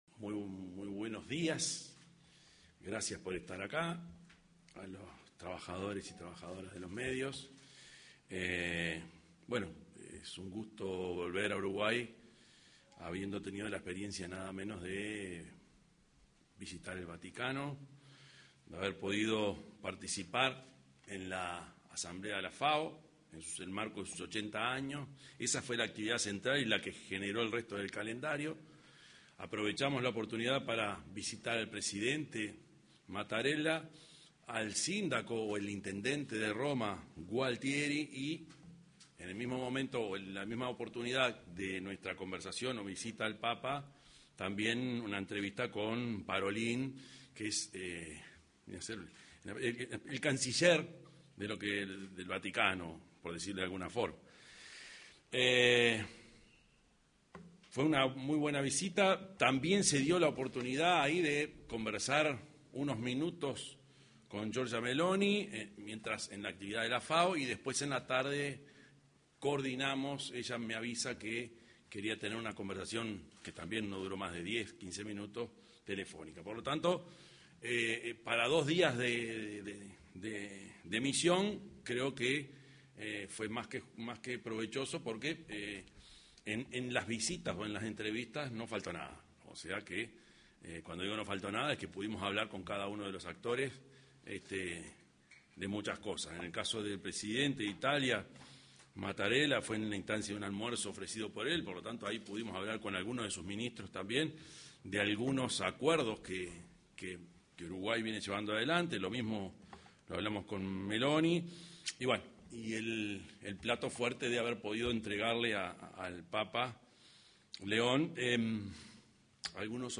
Declaraciones del presidente de la República, Yamandú Orsi, tras la visita a Italia
Declaraciones del presidente de la República, Yamandú Orsi, tras la visita a Italia 18/10/2025 Compartir Facebook X Copiar enlace WhatsApp LinkedIn El presidente de la República, Yamandú Orsi, arribó al país luego de su viaje a Italia, al llegar a la Base Aérea n° 1, luego del traspaso de mando, brindó una conferencia de prensa.